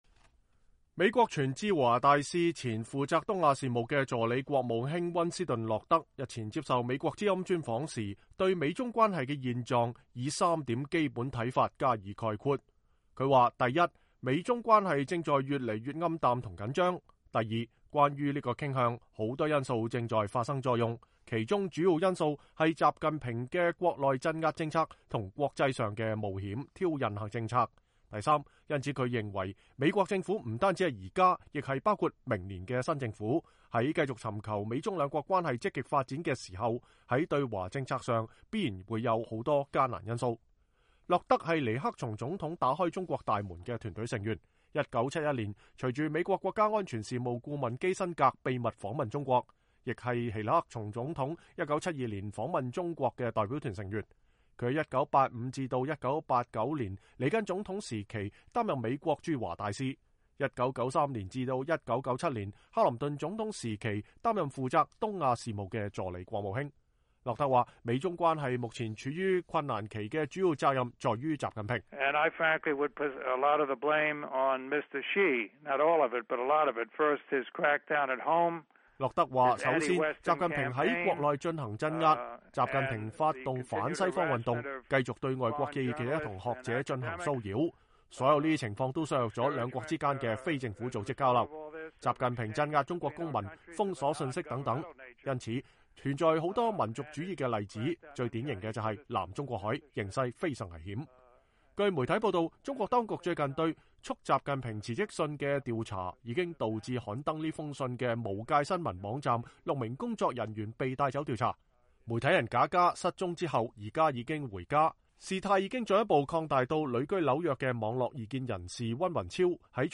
中國國家主席習近平來美參加核安全峰會並與奧巴馬總統會晤之際，前美國駐華大使溫斯頓洛德(Winston Lord)接受美國之音專訪，他指出，習近平對內鎮壓、對外冒險挑釁的政策，已經使美中關係陷於暗淡和緊張。